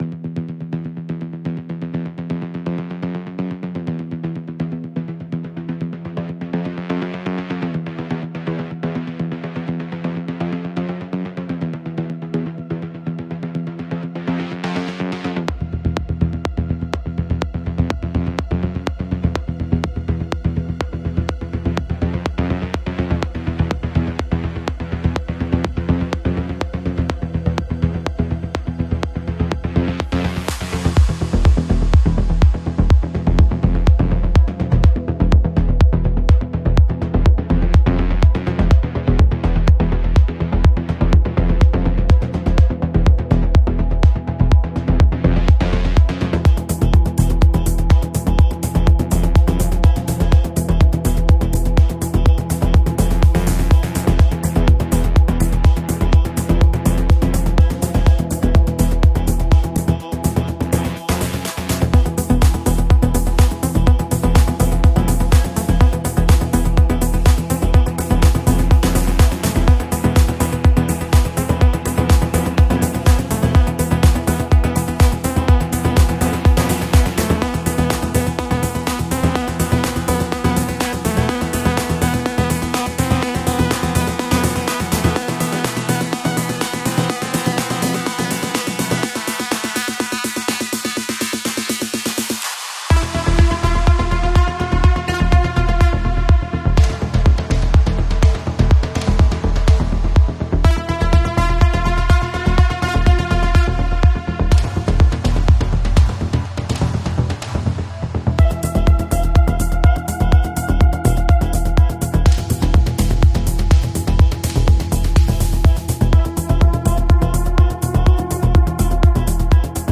Genre: Melodic Techno